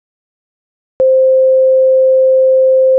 wav_silence_then_tone_sample.wav